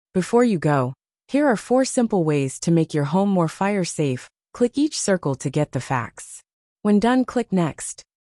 Text-to-Speech Audio for Narration
We use AI-generated text-to-speech audio to narrate digital learning.